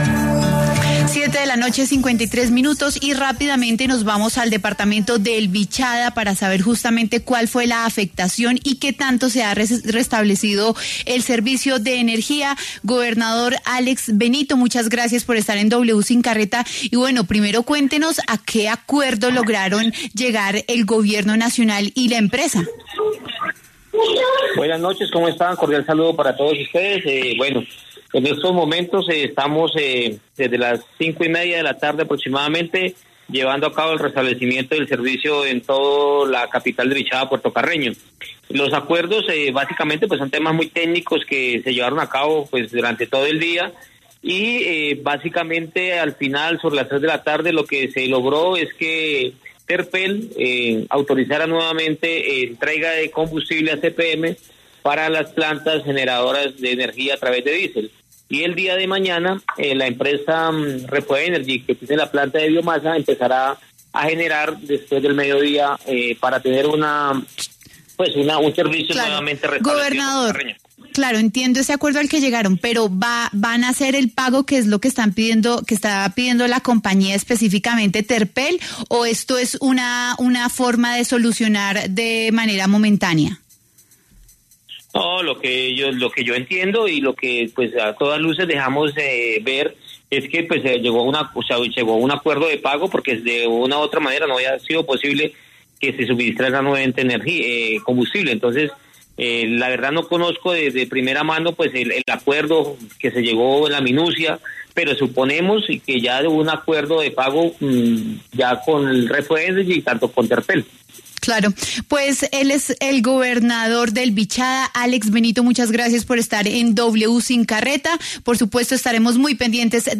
El gobernador del Vichada, Alexys Benito, conversó con W Sin Carreta sobre el restablecimiento del servicio de energía en Puerto Carreño y el acuerdo logrado con REFOENERGY.